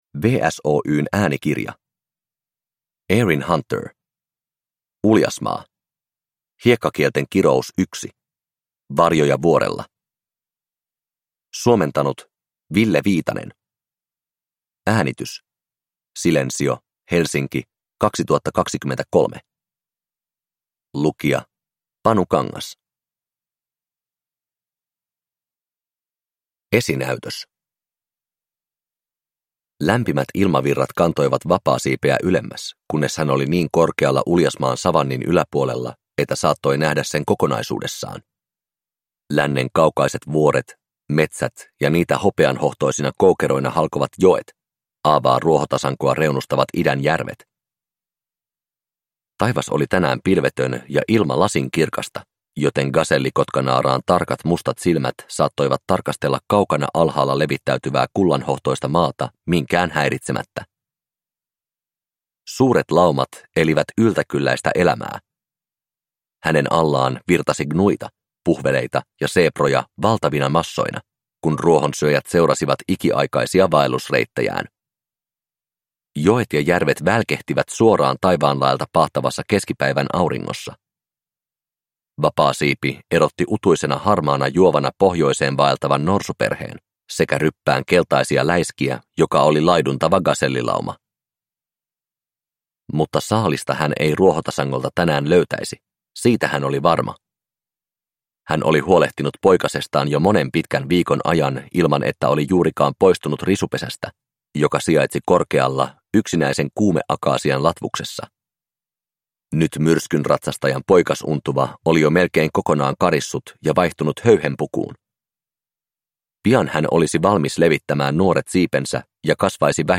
Uljasmaa: Hiekkakielten kirous 1: Varjoja vuorella – Ljudbok